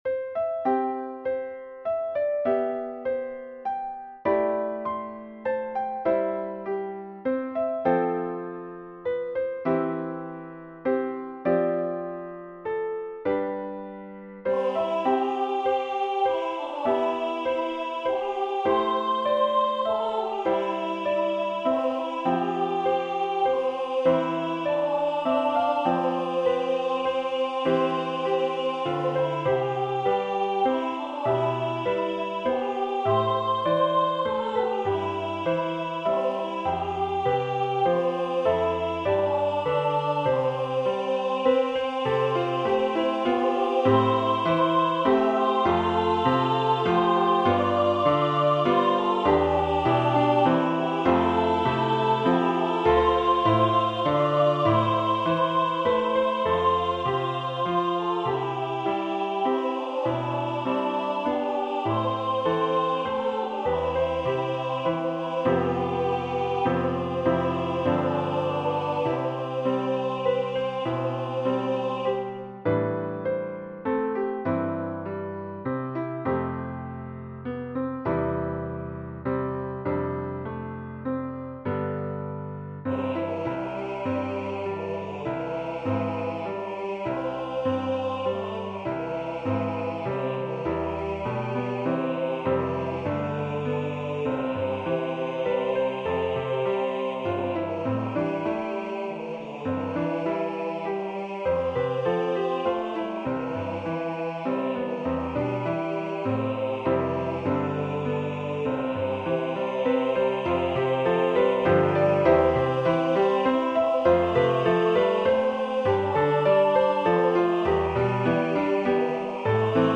My Shepherd Will Supply My Need (Hymn #1014) SATB with Piano Accompaniment. It is written for pop-up/insta choirs where the parts are straight out of the hymnbook.
Voicing/Instrumentation: SATB We also have other 9 arrangements of " My Shepherd Will Supply My Need ".